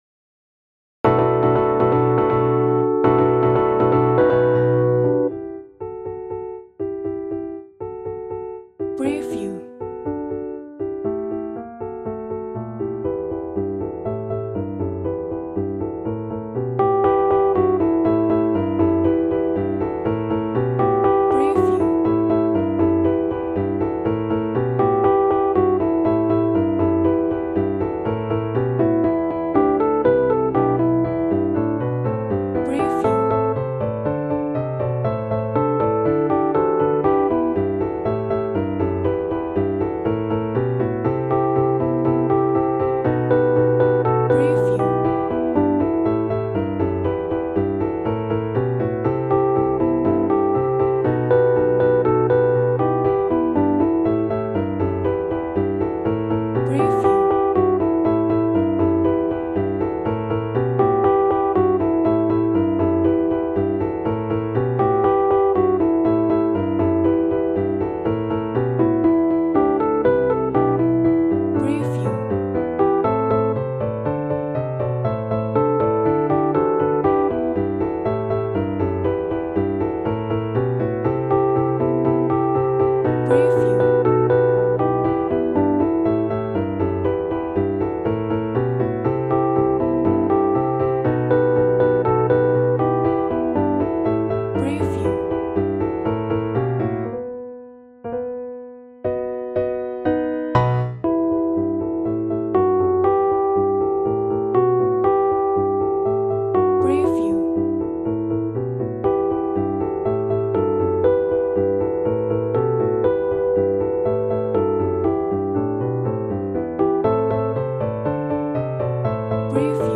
Тип: з супроводом Вид хору: SSA/Piano Жанр